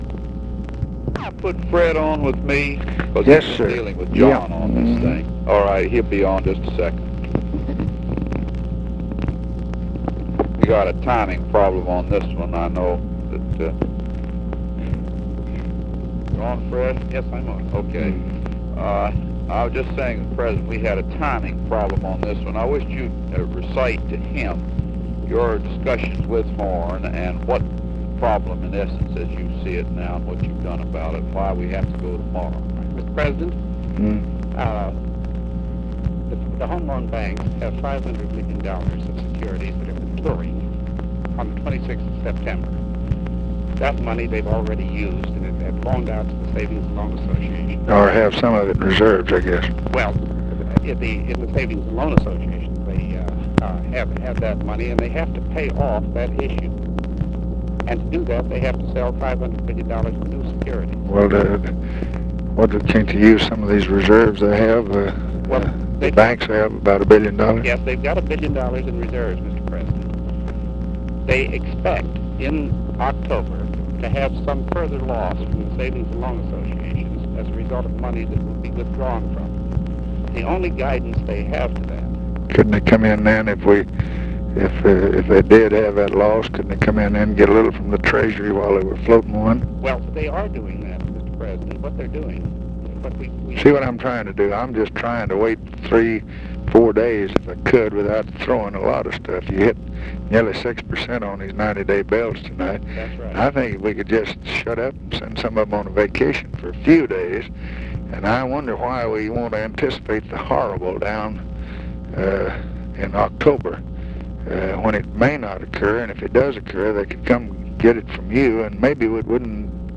Conversation with HENRY FOWLER, September 12, 1966
Secret White House Tapes